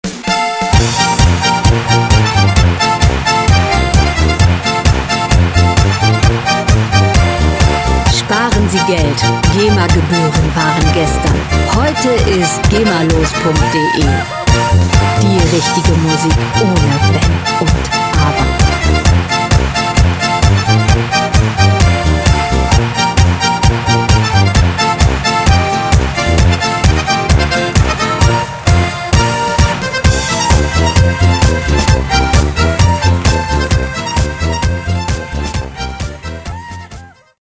Schlager Pop Musik - Aprés Ski
Musikstil: Volksmusik
Tempo: 131 bpm
Tonart: B-Dur
Charakter: stimmungsvoll, lebenslustig
Instrumentierung: Tuba, Geige, Klarinette, Akkordeon